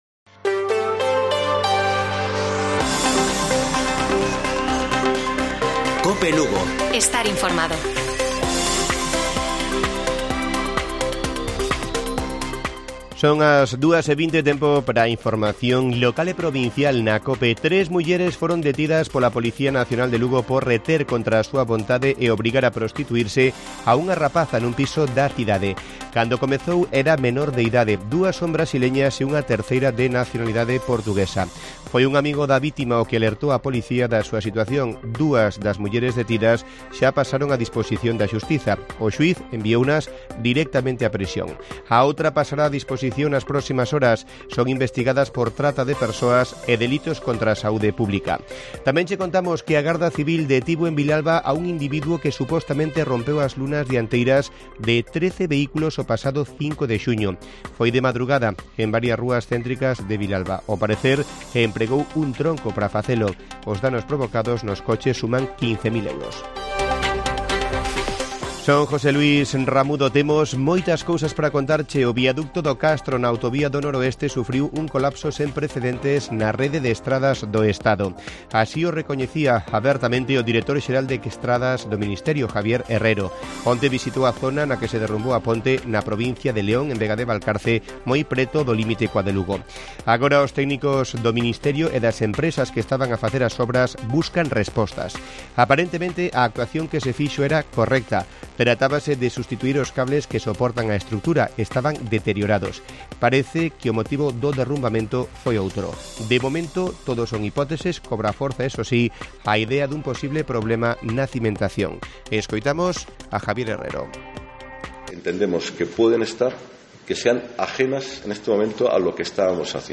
Informativo Mediodía de Cope Lugo. 09 DE JUNIO. 14:20 horas